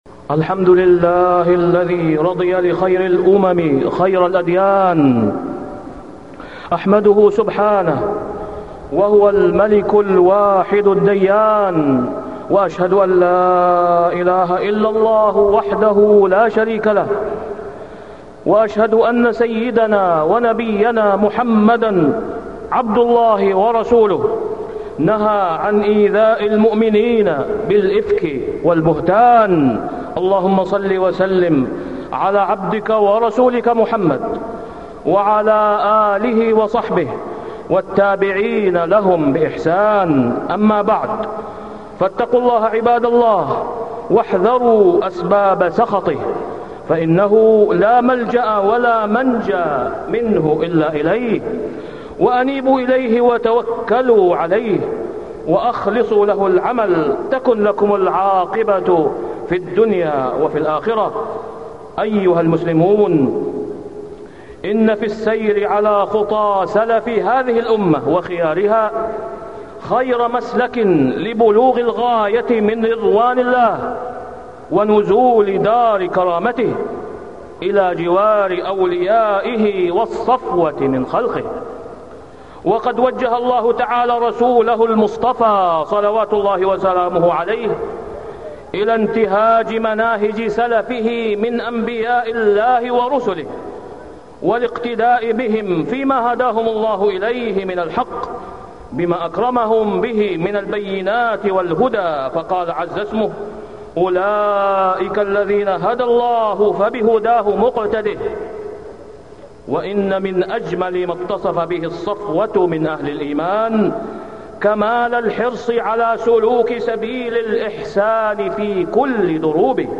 تاريخ النشر ٤ رجب ١٤٢٥ هـ المكان: المسجد الحرام الشيخ: فضيلة الشيخ د. أسامة بن عبدالله خياط فضيلة الشيخ د. أسامة بن عبدالله خياط النهي عن أذية المسلمين